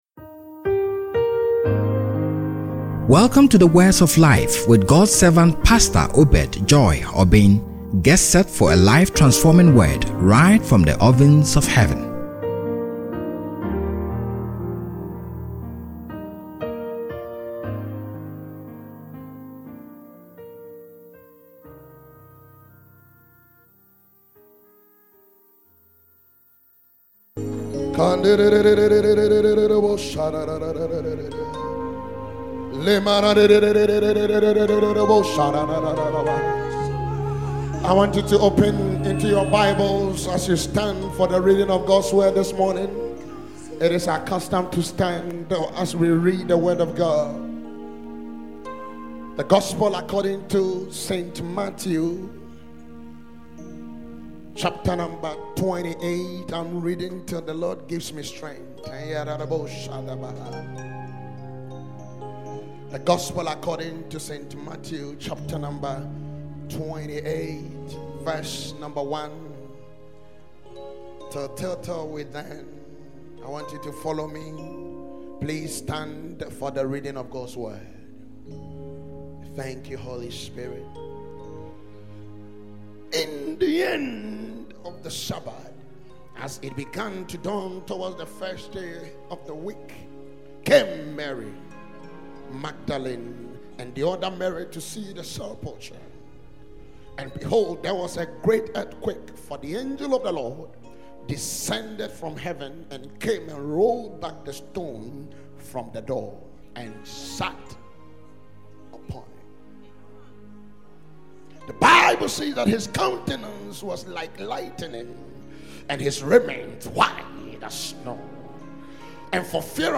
Easter Sunday (Live Resurrection Service)